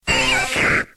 Cri d'Aspicot dans Pokémon X et Y.